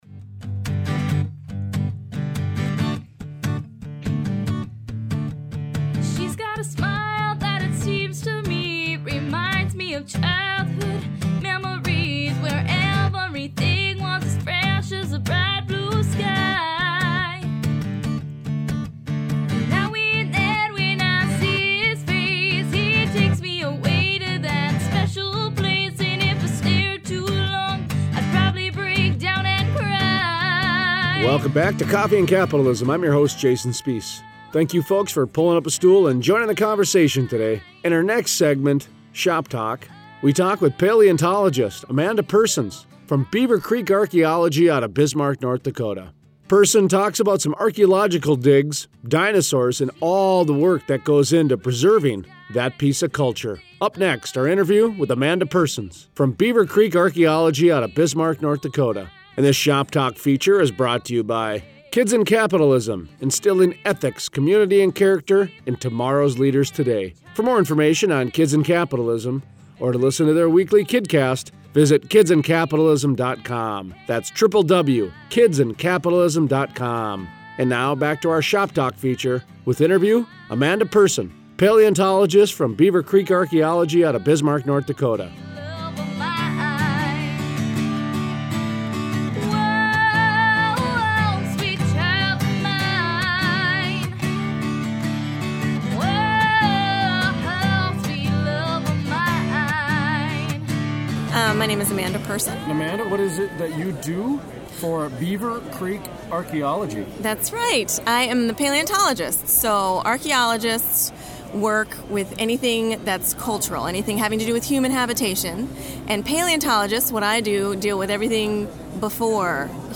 Shop Talk Interview